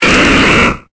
Cri d'Ectoplasma dans Pokémon Épée et Bouclier.